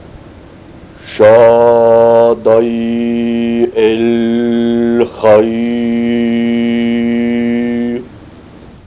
Bu yazıda ayrıca ritüel sırasında zikredilen bazı sözlerin ses dosyaları vardır ve mavi gözüken bu kelimeleri tıklandığında nasıl telaffuz edildikleri sesli bir biçimde gösterilecektir.